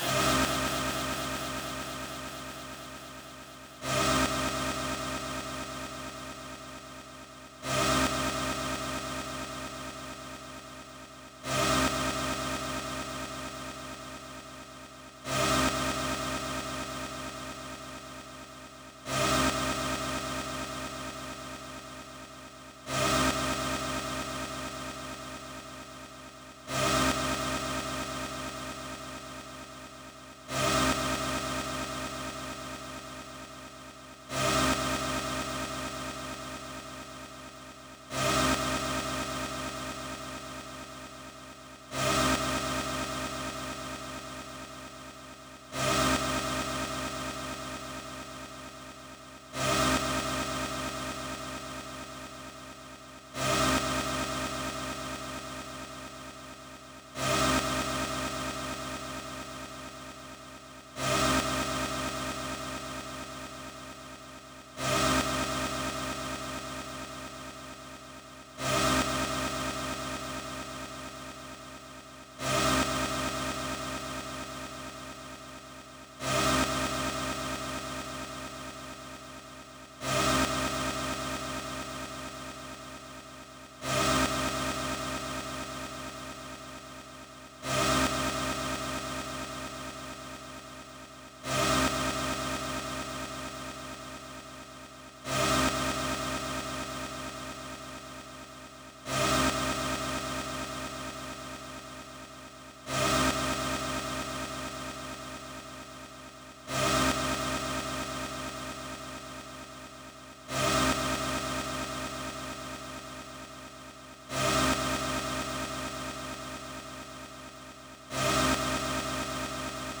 • rave sequence stab tirger 1 - Cm - 130.wav
Royalty free samples, freshly ripped from a rompler, containing sounds of the early rave and hardcore from the 90′s. These can represent a great boost to your techno/hard techno/dance production.
rave_sequence_stab_tirger_1_-_Cm_-_130_R7f.wav